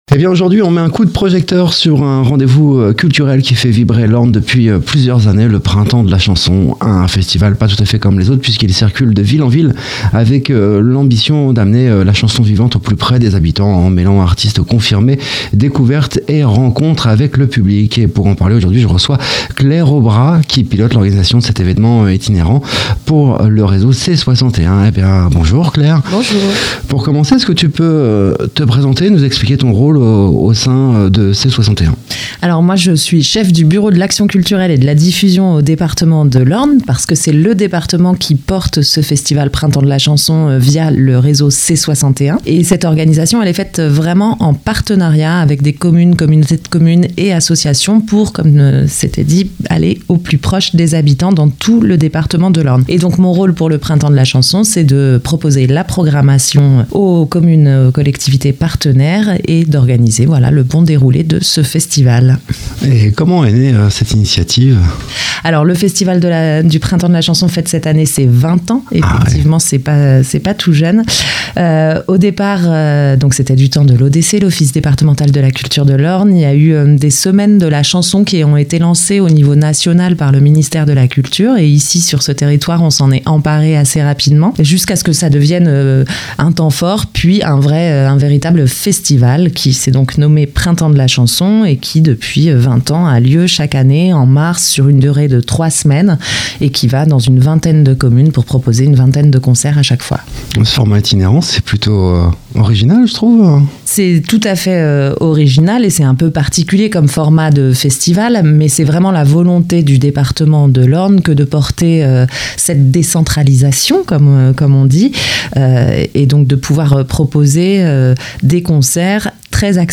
Un entretien qui permet de mieux comprendre comment se construit ce rendez-vous musical attendu chaque année, et de découvrir quelques-uns des temps forts de cette nouvelle édition du Printemps de la chanson. culture local festival orne chanson